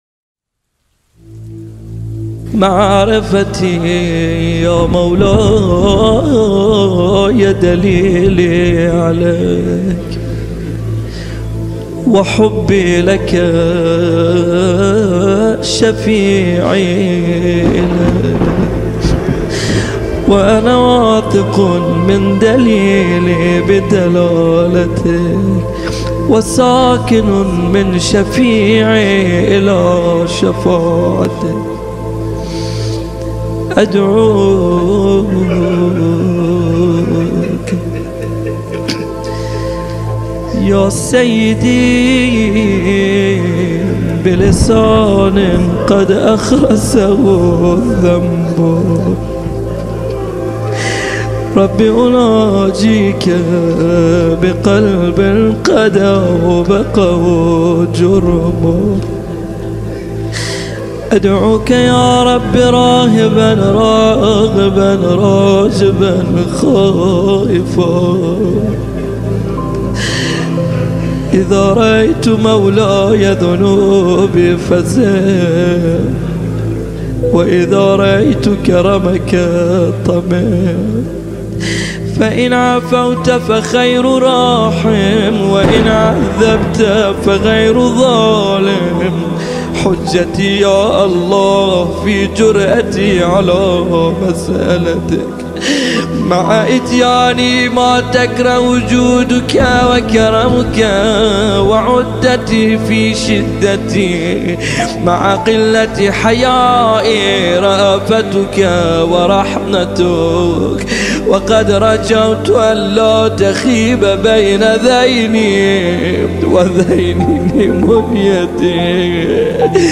قرائت فرازی از دعای ابوحمزه ثمالی